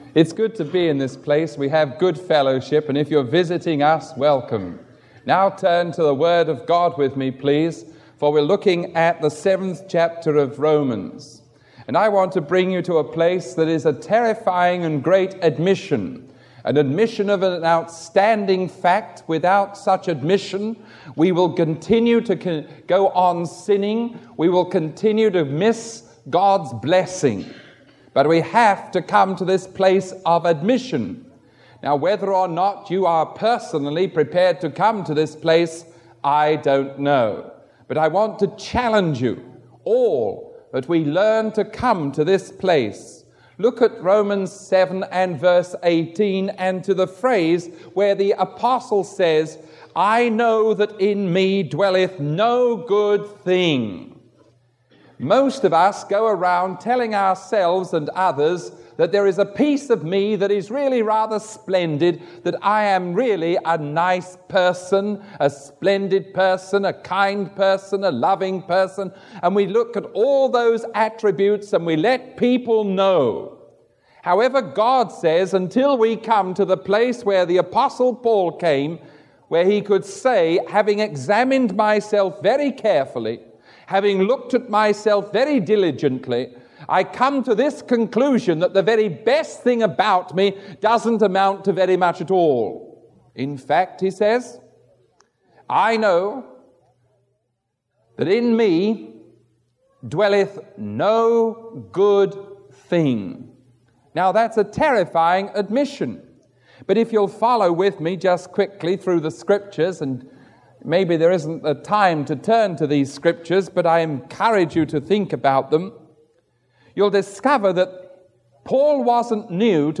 Sermon 0475A recorded on January 10